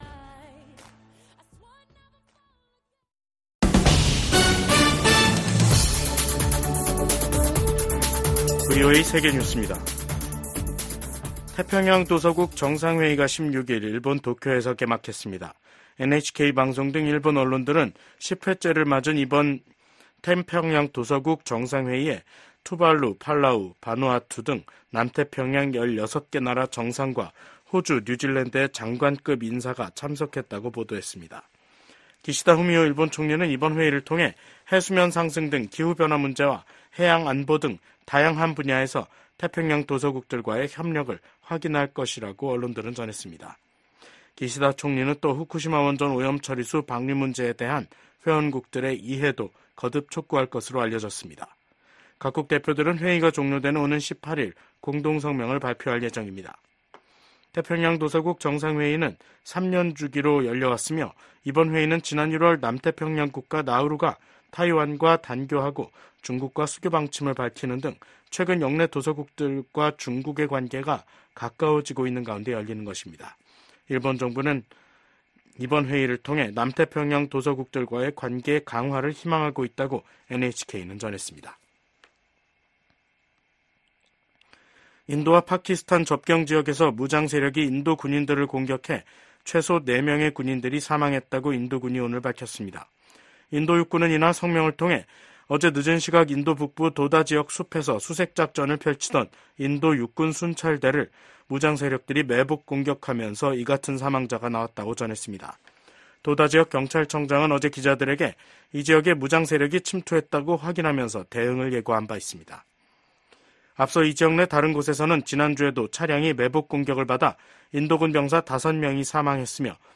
VOA 한국어 간판 뉴스 프로그램 '뉴스 투데이', 2024년 7월 16일 3부 방송입니다. 도널드 트럼프 전 미국 대통령이 공화당 대선 후보로 공식 지명됐습니다.